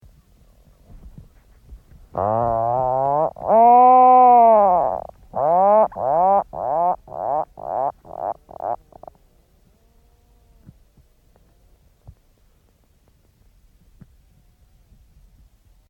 Birdsong 5